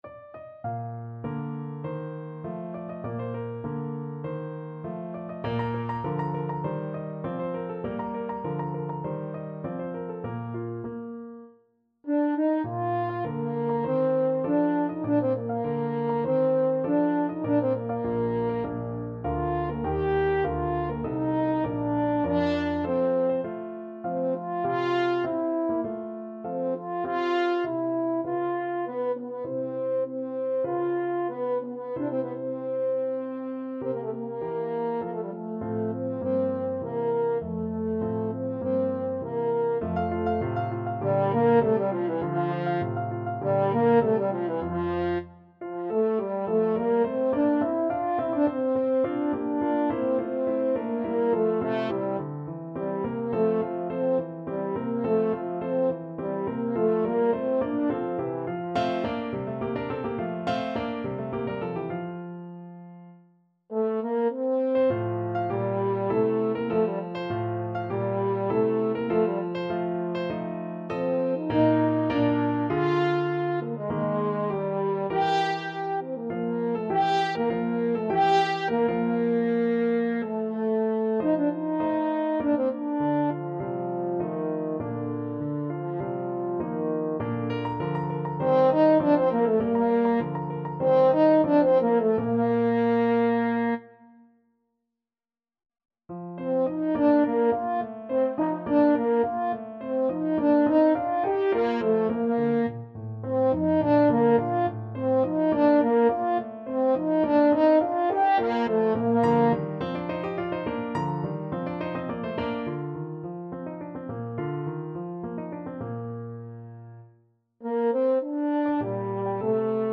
French Horn
Bb major (Sounding Pitch) F major (French Horn in F) (View more Bb major Music for French Horn )
2/4 (View more 2/4 Music)
~ = 50 Larghetto
Classical (View more Classical French Horn Music)